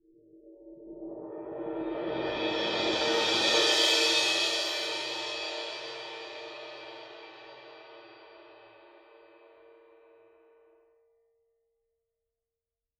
susCymb1-cresc-Median_v1.wav